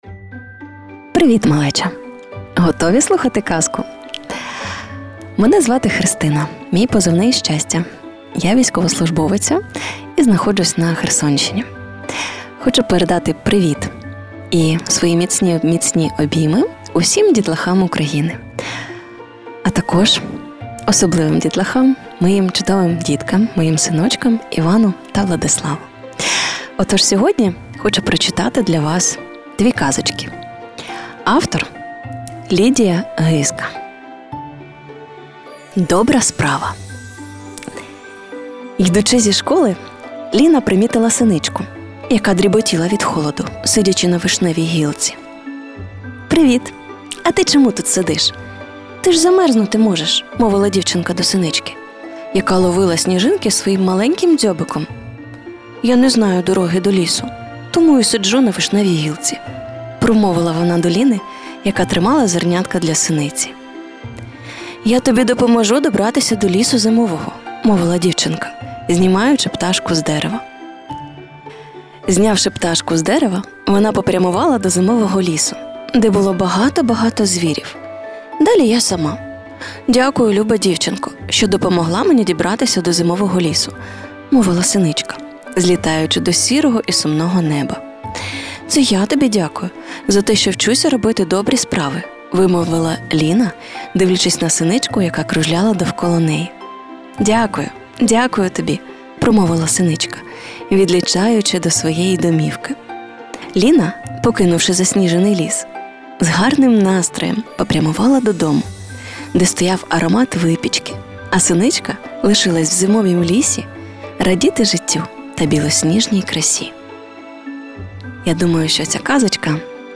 Історії від сучасних українських авторів зачитають батьки, які попри будь-яку відстань завжди поруч. Казки від тих, хто на Херсонщині захищає, долає щоденні наслідки від ворожих атак, працює постійно чи знаходиться у робочому відрядженні в різних установах.